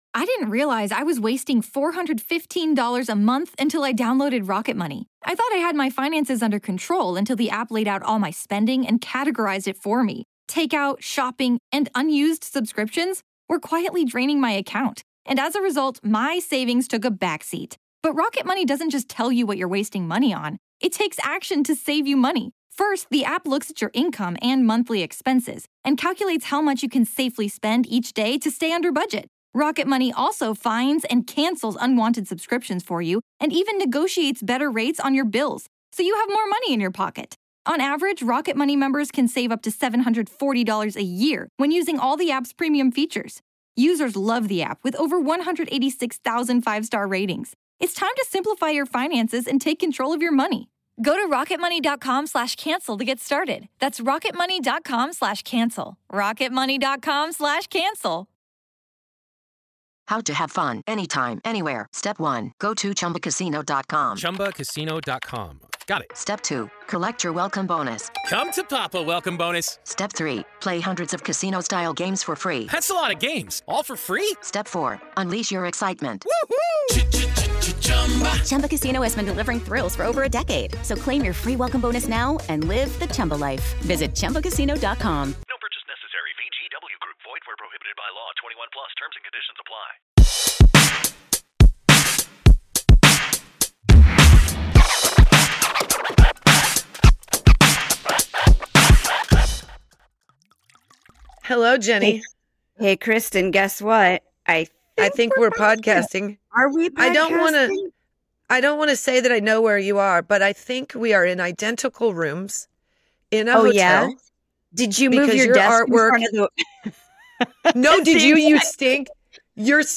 Today we’re podcasting from the road and diving into the chaos and joy.